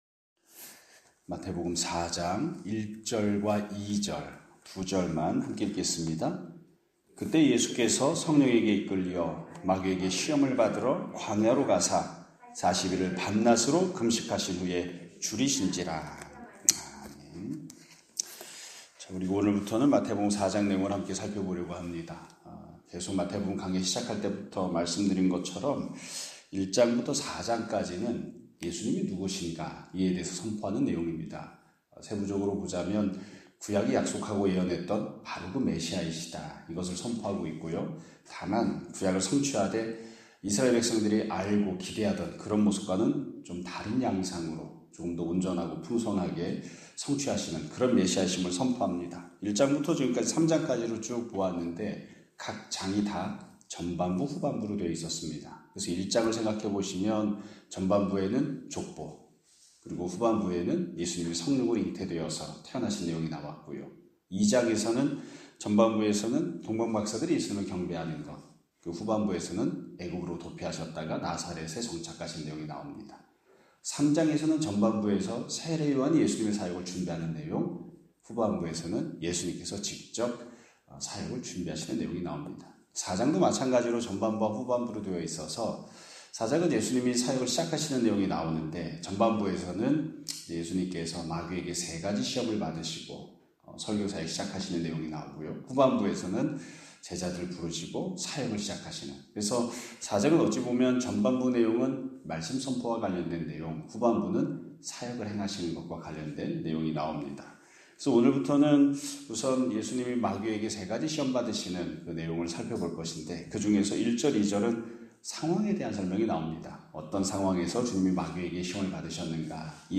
2025년 4월 23일(수요일) <아침예배> 설교입니다.